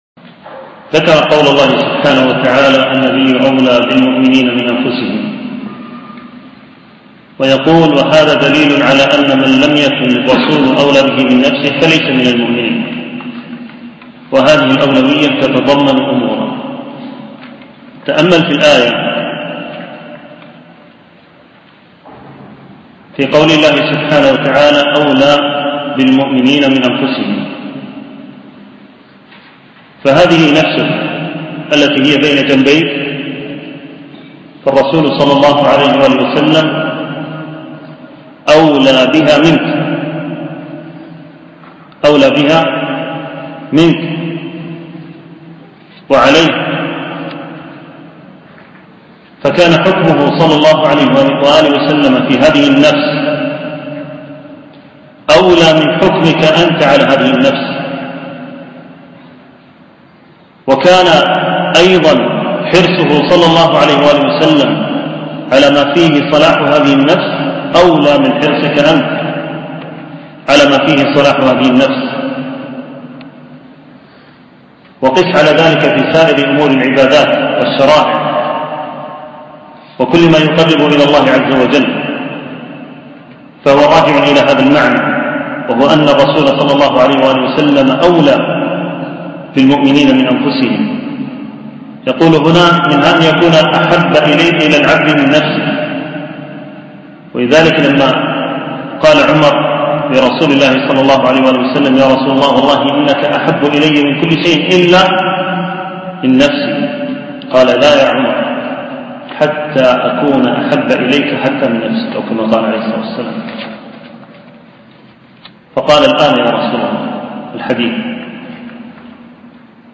التنسيق: MP3 Mono 22kHz 32Kbps (VBR)